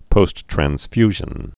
(pōsttrăns-fyzhən)